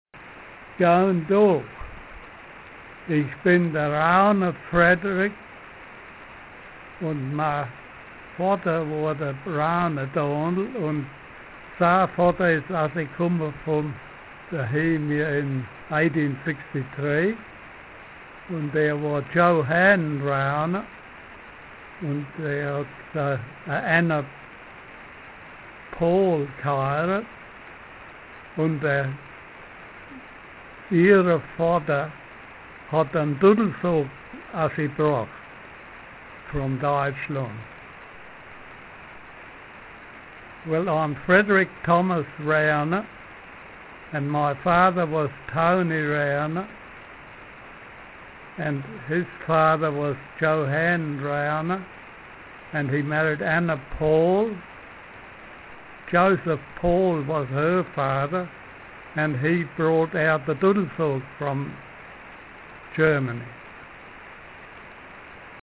Puhoi Egerländer Dialect
Meet the informants (speakers)